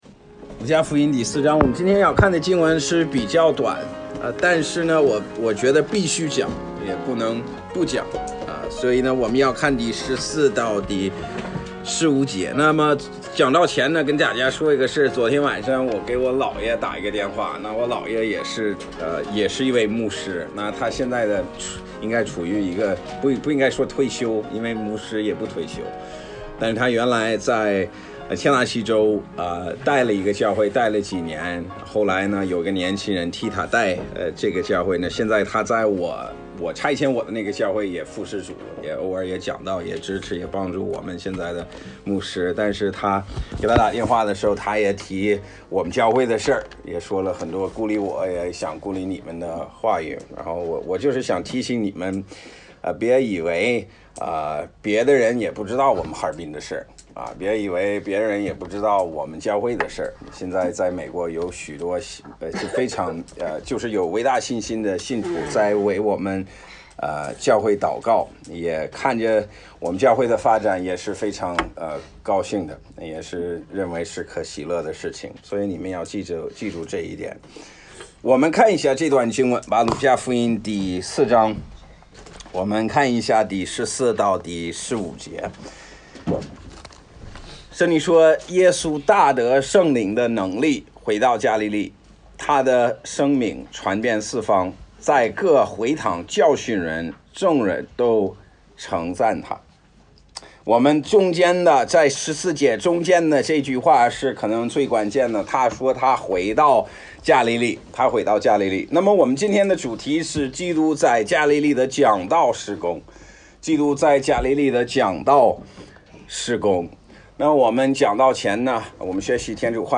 基督在迦利利的讲道事工.mp3